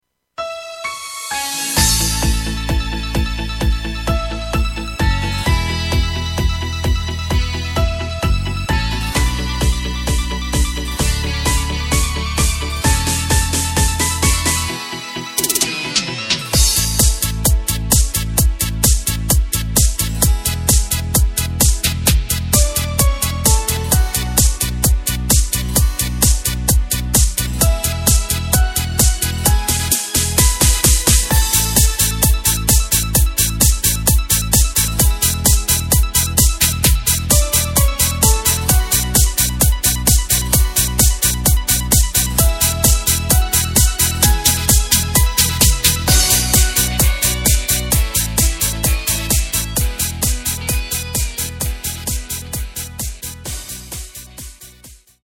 Takt:          4/4
Tempo:         130.00
Tonart:            A
Discofox aus dem Jahr 2014!
Playback mp3 Demo